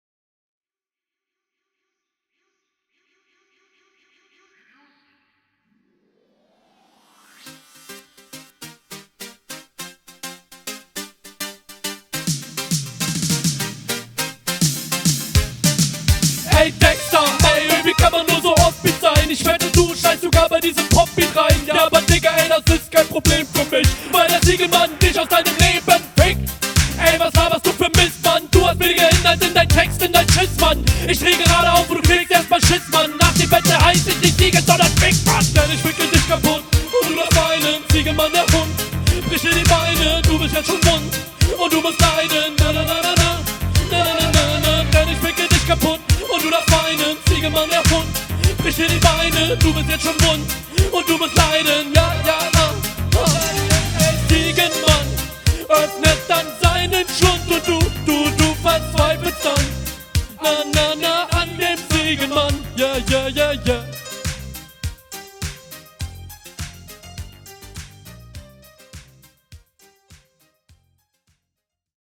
Flow: Flow is sehr nice. Kommt sehr gut auf den Beat. Auch dieses: dadadada. oder …
Der Stimmeinsatz ist schwächer als in der Runde davor, wahrscheinlich weil der Beat ihm gerade …
Flow: Also der Flow ist diesmal echt gut. Vorallem der Anfang. Du ratterst schön durch …
Flow: Sehr nice, Delivery passt und es klingt so locker geflowt und ich glaub er …